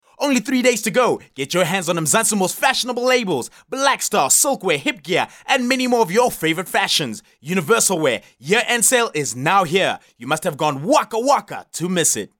Vibrant voice artist,versitile,outspoken,south african voice,bright colourful voice,strong radio voice
Sprechprobe: Sonstiges (Muttersprache):
South African bright young male voice